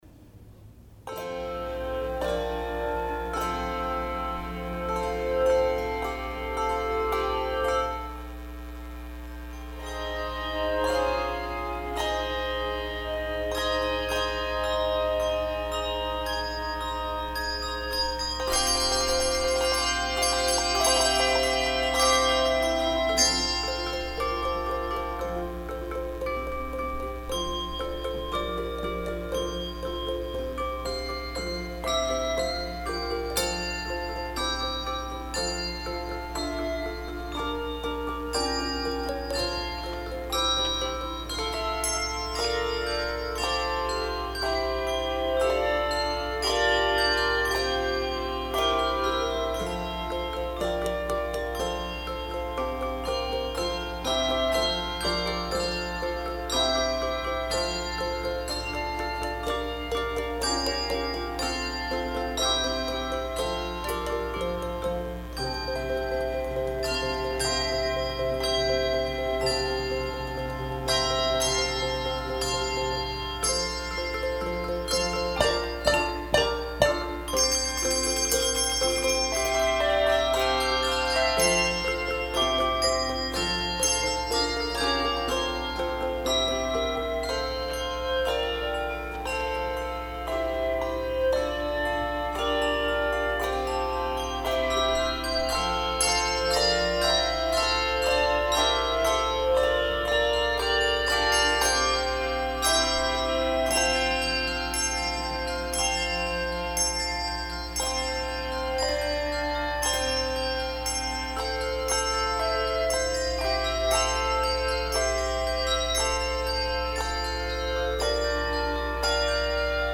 Guide Me, O Thou Great Jehovah arr. Michael Helman, Handbell Choir
Performer:  Handbell Choir